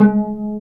Index of /90_sSampleCDs/Roland LCDP13 String Sections/STR_Vcs Marc&Piz/STR_Vcs Pz.3 dry